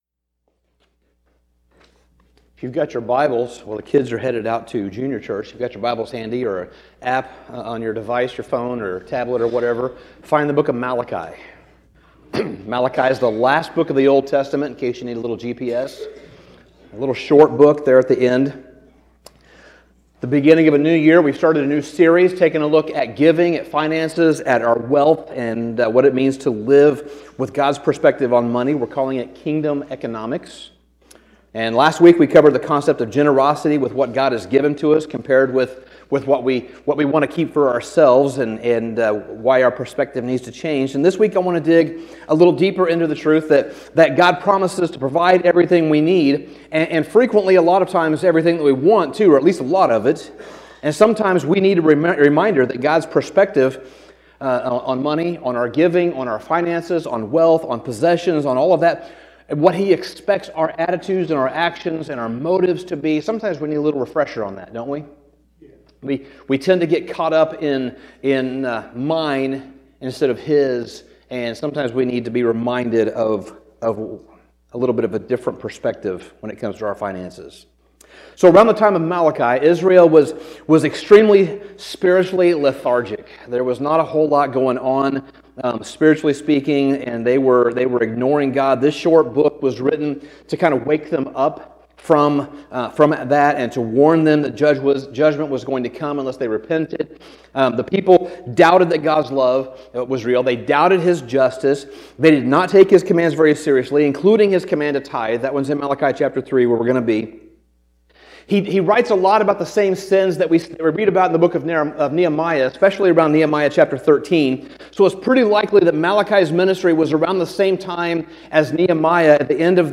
Sermon Summary Tithing has a way of bringing our faith out of the abstract and into the everyday details of life. At its core, a tithe is simply a tenth, but it’s more than a percentage — it’s a priority.